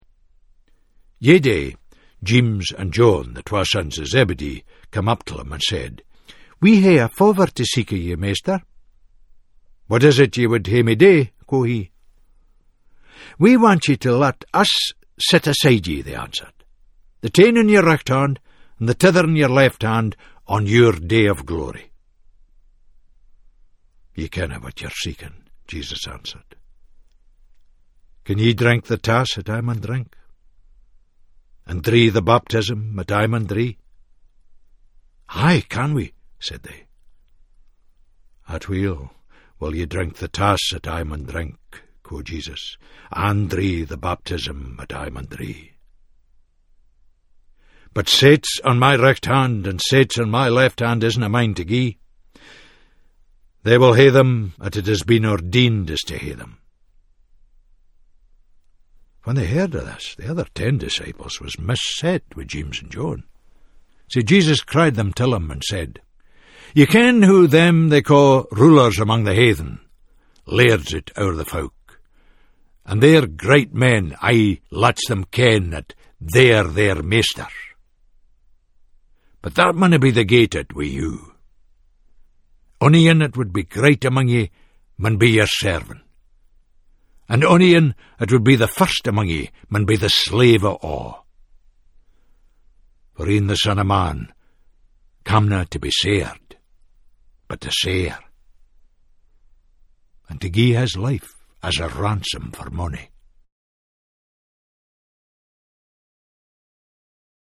In the recordings of the Scots New Testament, Tom Fleming brings the well known stories of the gospels to life in a distinct Scots voice.